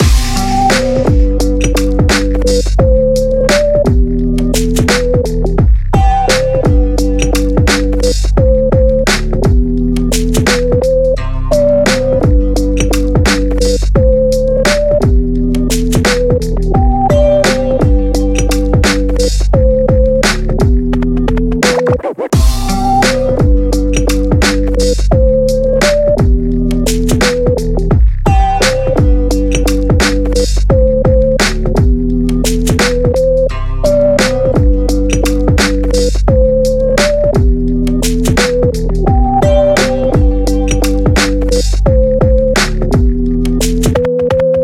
BPM86、Em/Gmaj、同じドラムセット縛りの6パターンのビート・トラックです。
EMO HIPHOP LOOP TRACK BPM86 Em/Gmaj pattern A